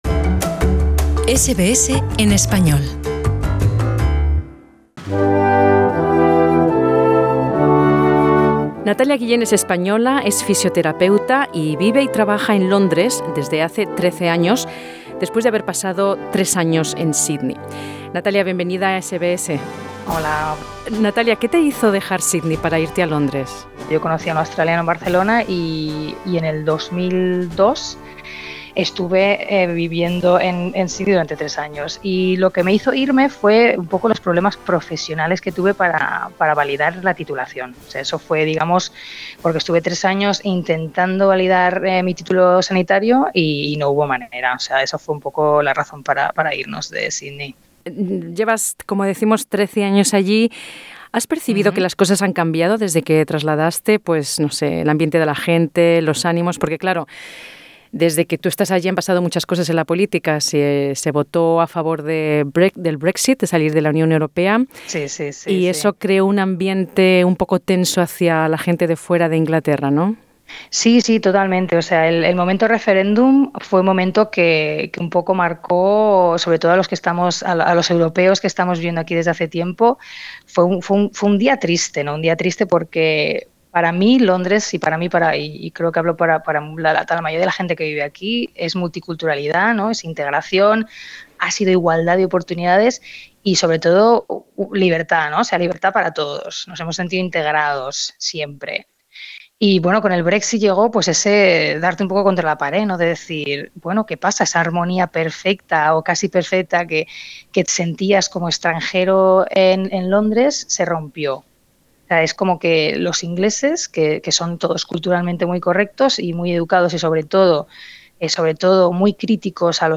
La incertidumbre crece entre los ciudadanos europeos viviendo en Gran Bretaña que tratan de tomar una decisión sobre cómo regular su estadía en el país. SBS Spanish habló con una española que afirma que las cosas han "cambiado completamente" desde el referendum del Brexit.